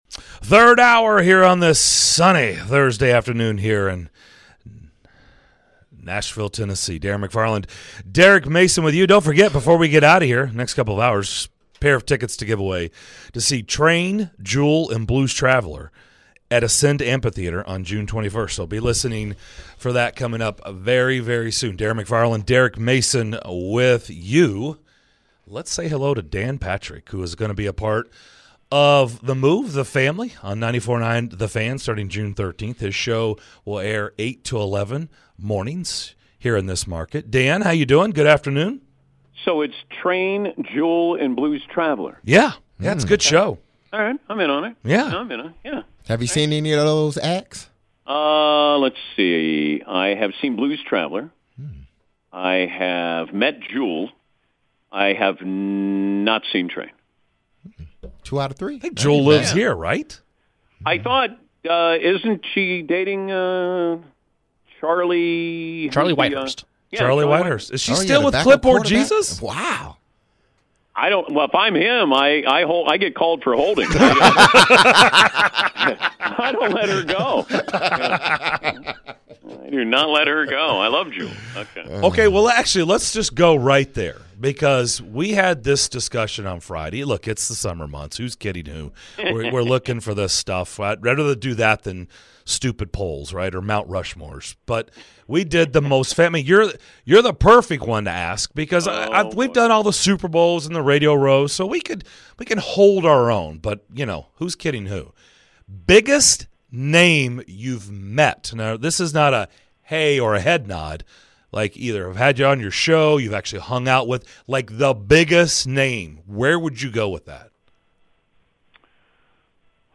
In the third hour of the show: the guys chat with sports radio legend Dan Patrick